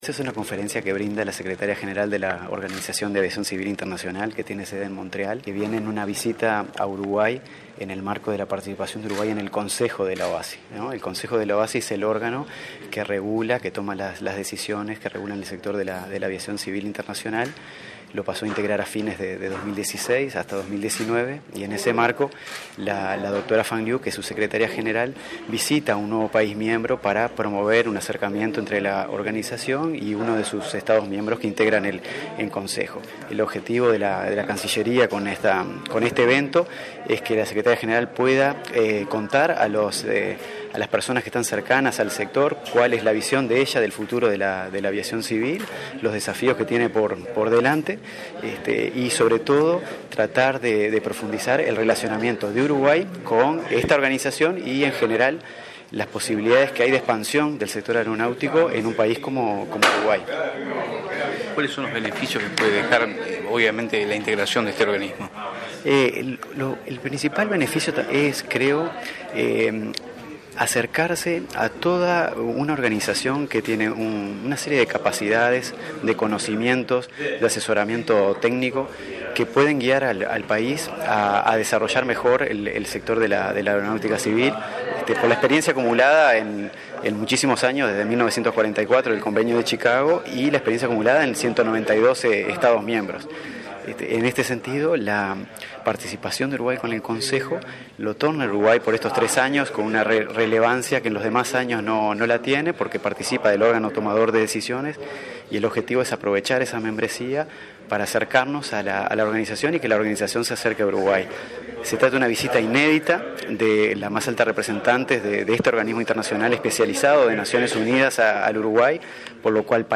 Debido a la visita de la secretaria general de la Organización de Aviación Civil Internacional de ONU, Fang Liu, la Cancillería realizó una conferencia acerca de las fortalezas y desafíos de una red global de transporte aéreo. Martin Vidal, representante de Uruguay en la organización, dijo que con esta visita se busca profundizar el relacionamiento y las posibilidades de expansión del sector.